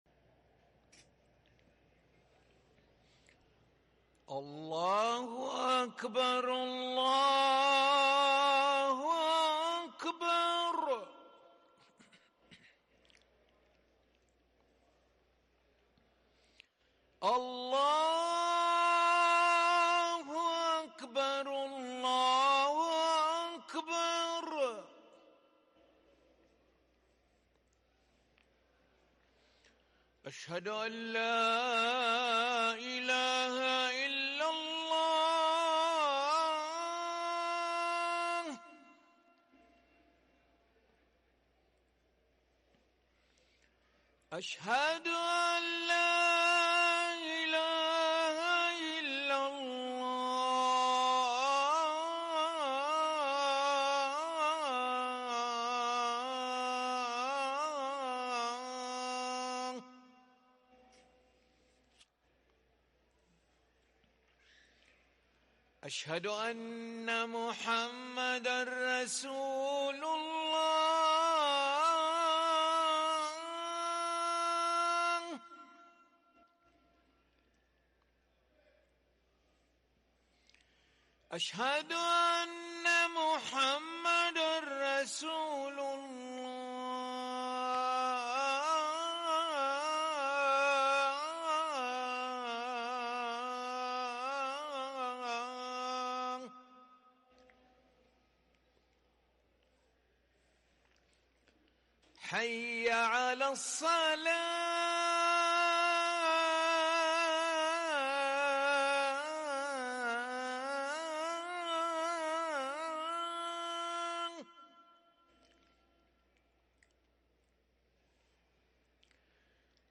أذان العشاء للمؤذن علي ملا الأحد 1 صفر 1444هـ > ١٤٤٤ 🕋 > ركن الأذان 🕋 > المزيد - تلاوات الحرمين